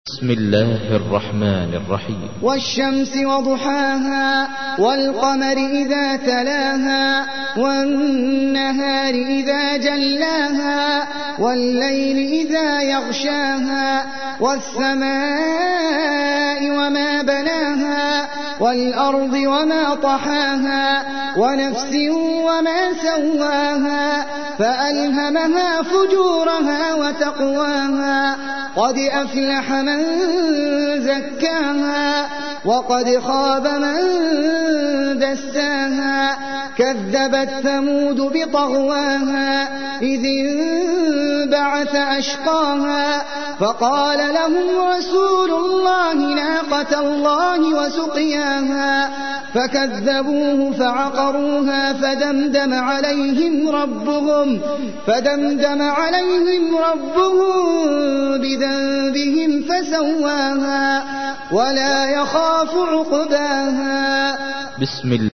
تحميل : 91. سورة الشمس / القارئ احمد العجمي / القرآن الكريم / موقع يا حسين